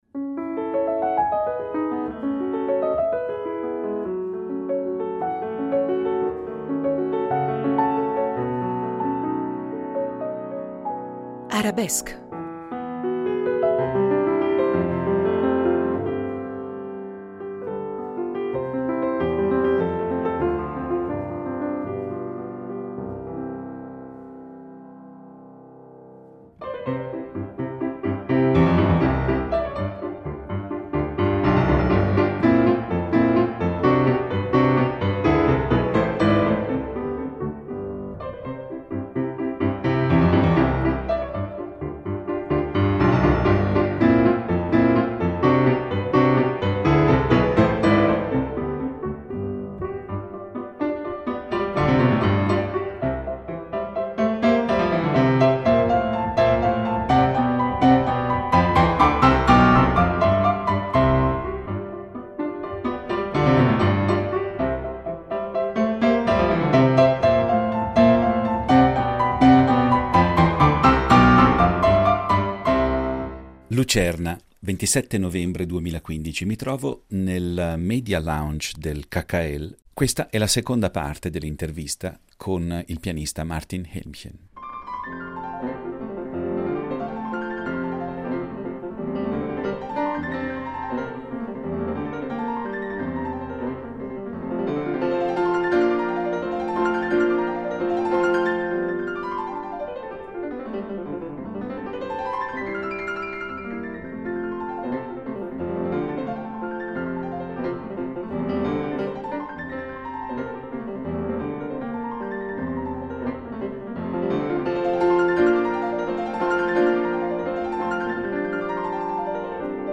Alcune delle interviste sono rimaste a lungo nel cassetto